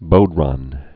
(bōrän, bou-)